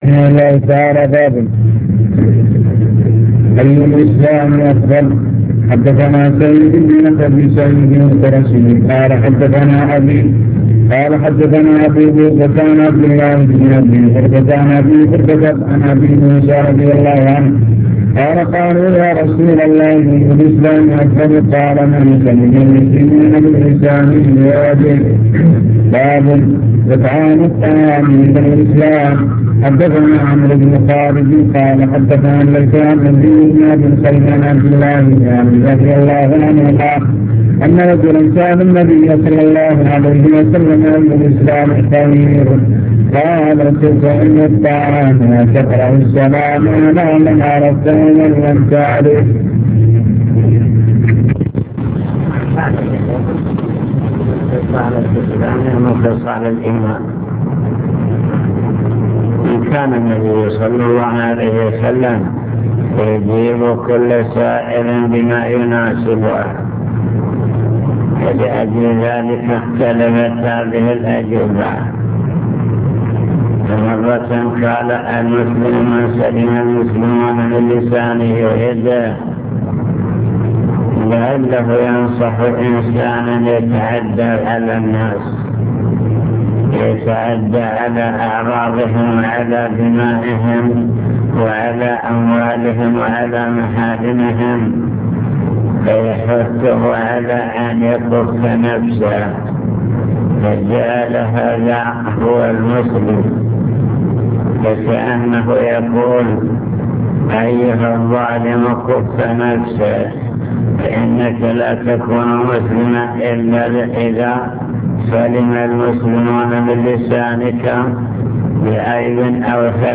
المكتبة الصوتية  تسجيلات - كتب  شرح كتاب الإيمان من صحيح البخاري